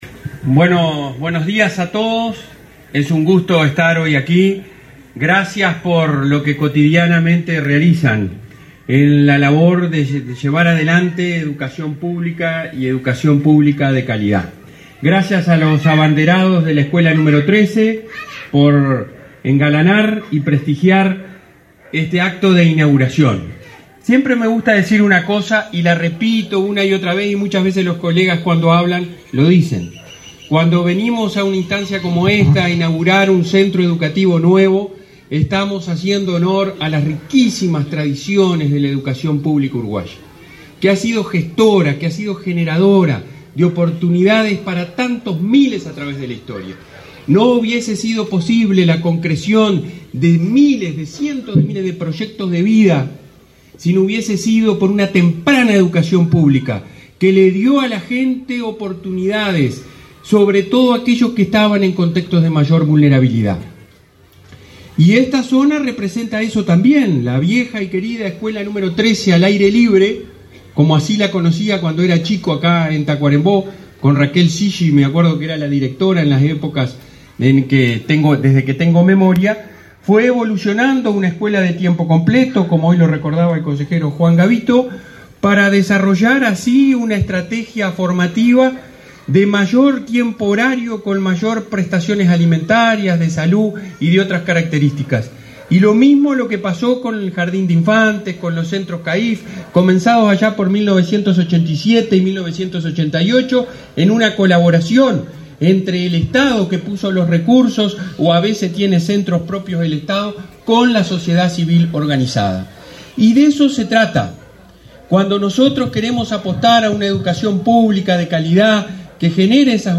Palabras del presidente del Codicen de la ANEP, Robert Silva
Palabras del presidente del Codicen de la ANEP, Robert Silva 09/06/2023 Compartir Facebook X Copiar enlace WhatsApp LinkedIn El presidente del Consejo Directivo Central (Codicen) de la Administración Nacional de Educación Pública (ANEP), Robert Silva, participó, este 9 de junio, en la inauguración del jardín n.° 151 y de la escuela n.° 71 de Tacuarembó.